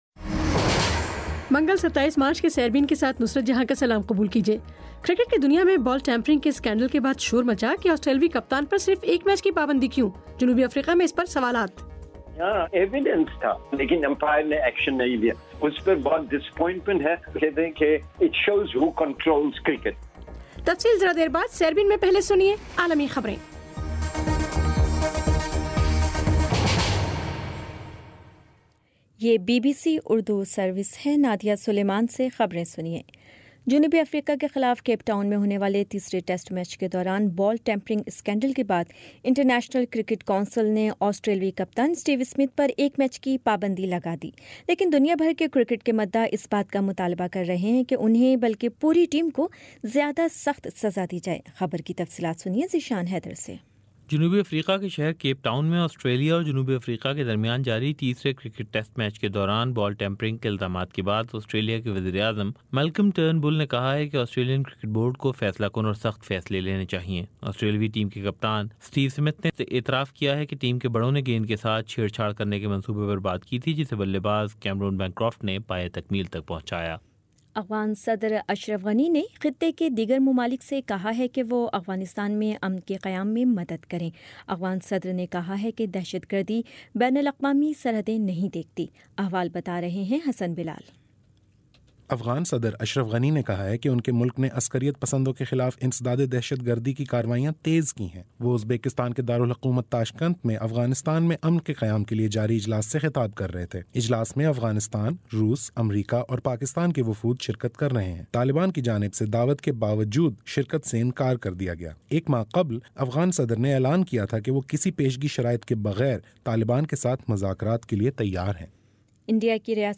منگل 27 مارچ کا سیربین ریڈیو پروگرام